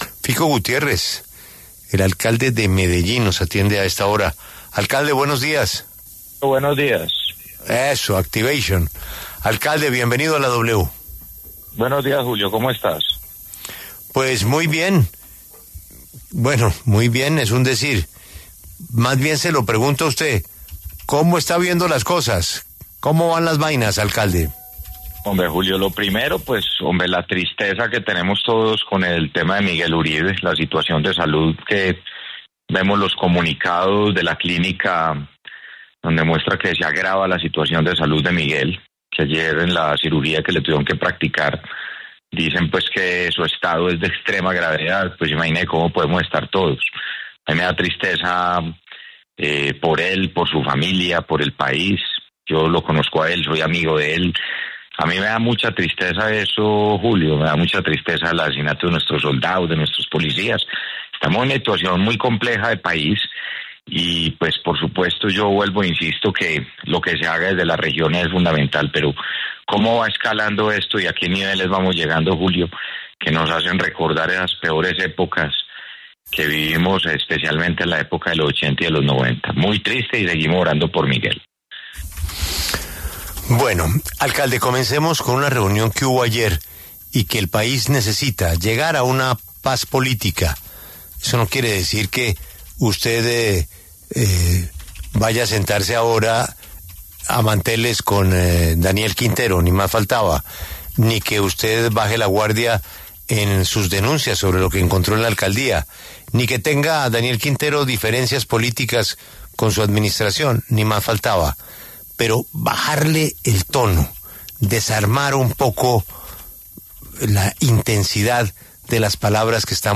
Federico Gutiérrez, alcalde de Medellín, habló en La W sobre la salud de Miguel Uribe y el llamado a “desarmar” el lenguaje para bajar el tono al debate político.